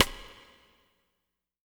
BW STICK02-L.wav